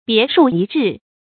注音：ㄅㄧㄝ ˊ ㄕㄨˋ ㄧ ㄓㄧˋ
別樹一幟的讀法